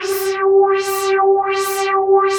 27 VOICES -L.wav